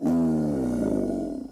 c_croc_dead.wav